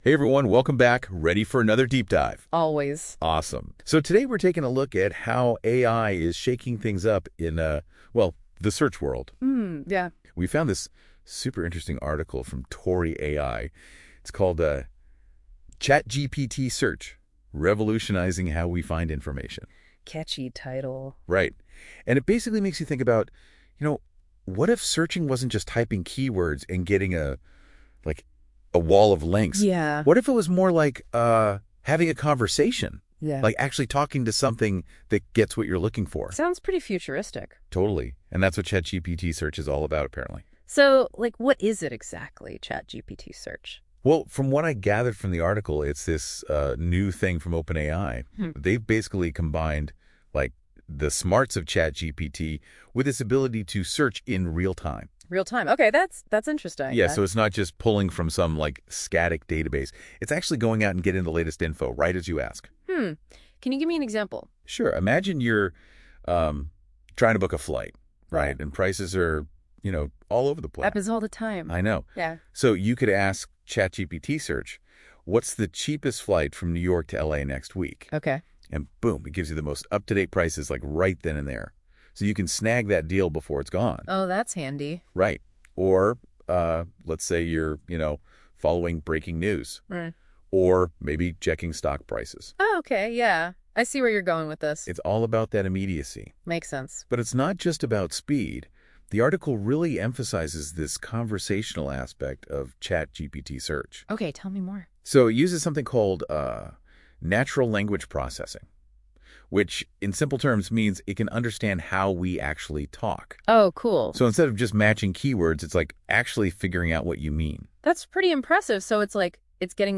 Listen to the podcast version of this blog post, created with Notebook LM, for deeper insights and practical takeaways: